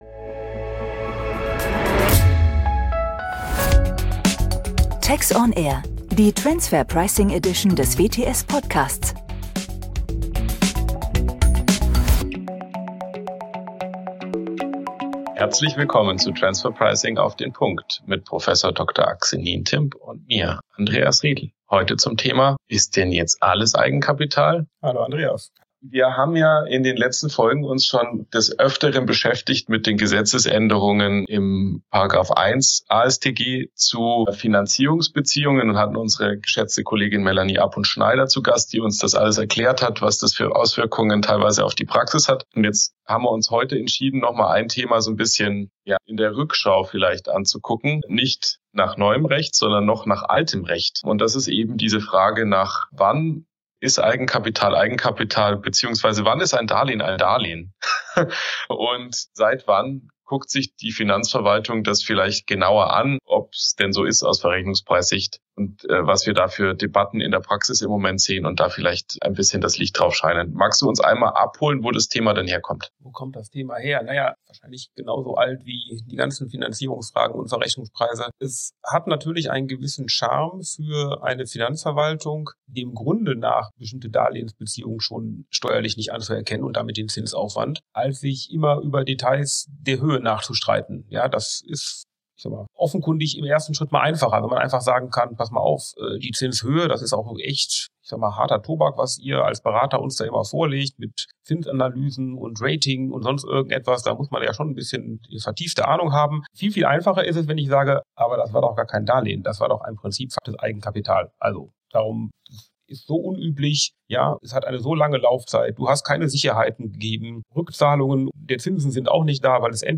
sodass sie in gewohnt lockerer Manier noch tiefer in das Thema eintauchen. Gemeinsam diskutieren sie die Fragen, ob und ab wann es sich um Eigenkapital handelt, wann ist ein Darlehen ein Darlehen und was für Debatten gibt es momentan über altes und neues Recht mit Blick in die Verrechnungspreispraxis.